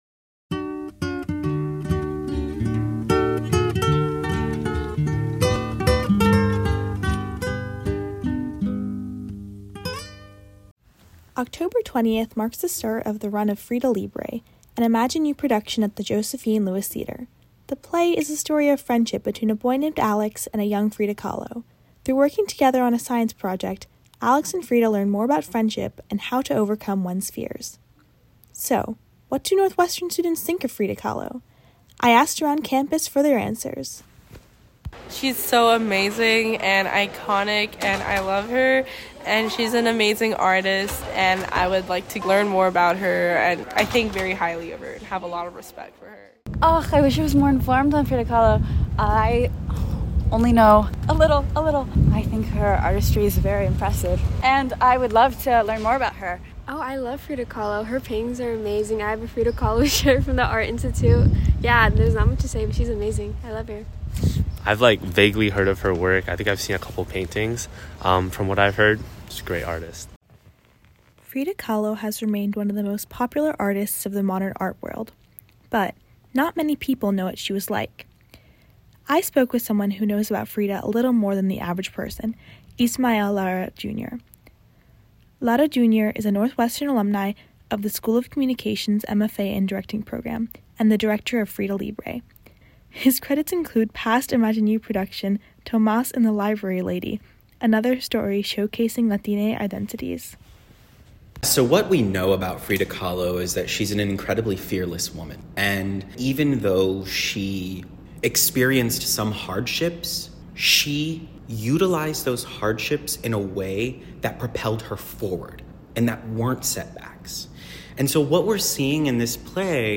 Chavela Vargas – “Paloma Negra” Music from Frida (2002) Soundtrack